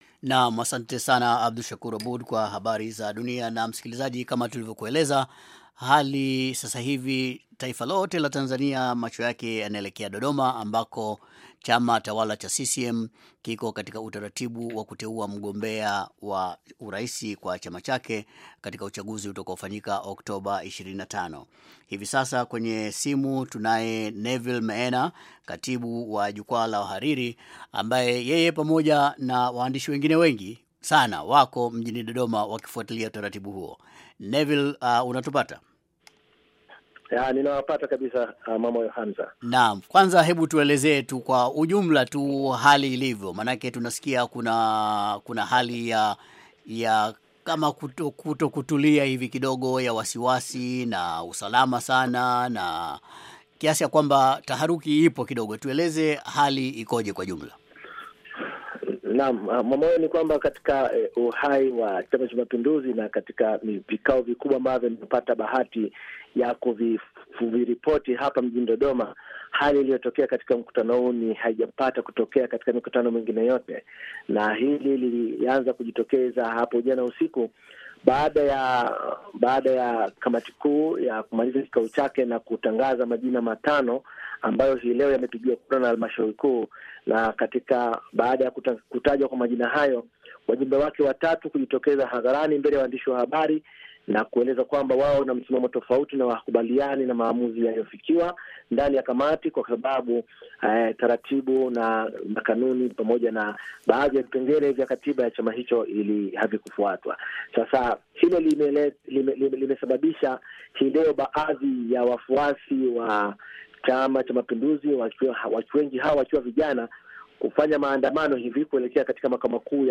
Wandishi habari